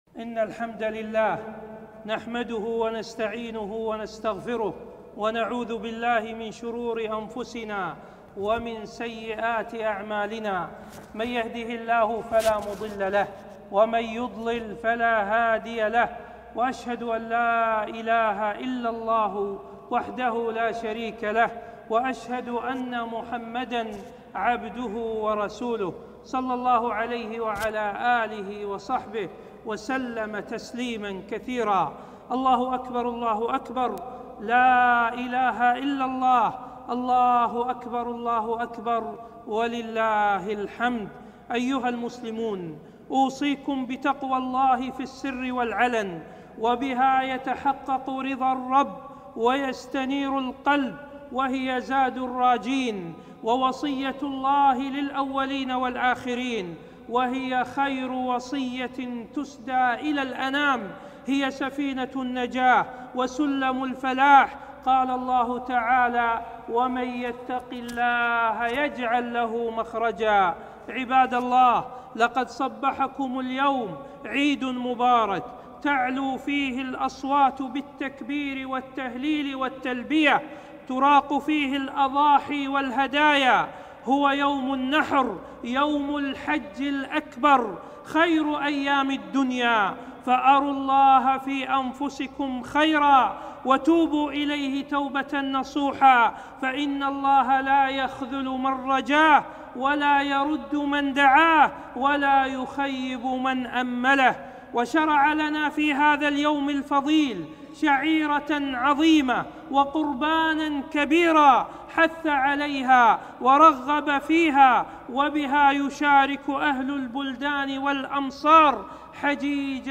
خطبة عيد الأضحى 1442هــ